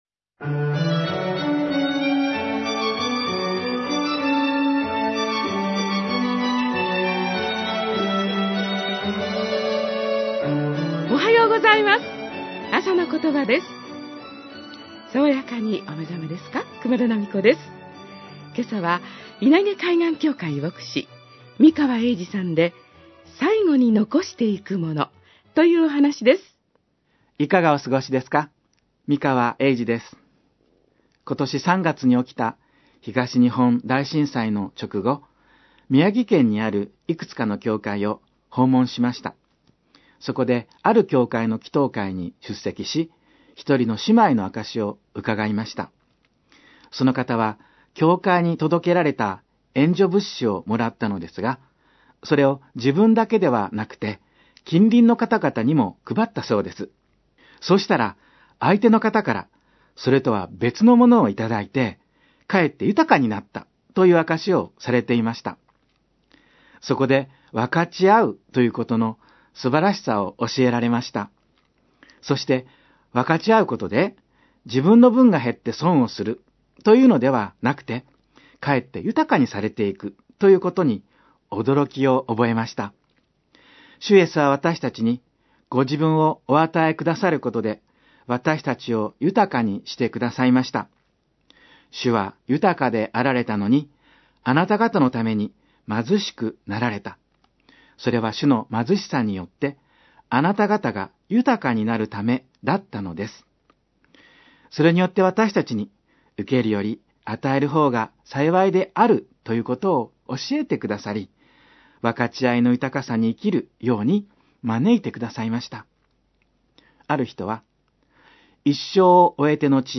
あさのことば 2011年11月25日（金）放送